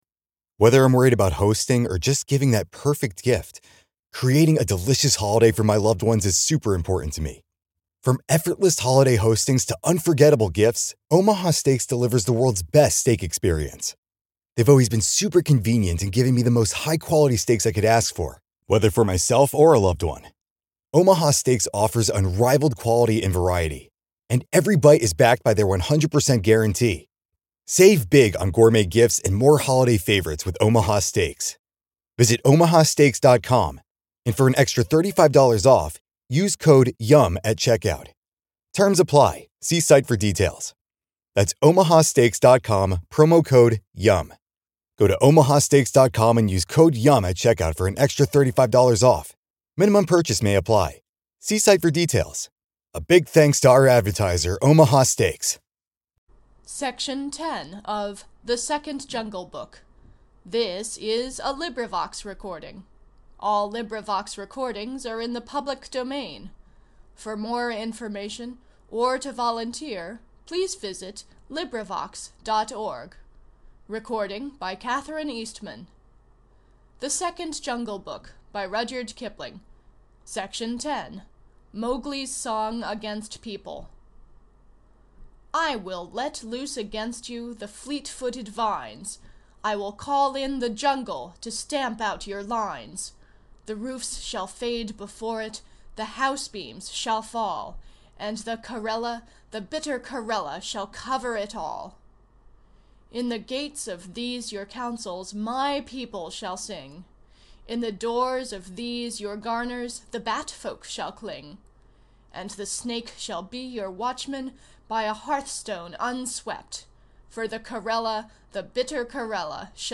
This is a collaborative reading.